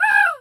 pgs/Assets/Audio/Animal_Impersonations/crow_raven_call_squawk_08.wav
crow_raven_call_squawk_08.wav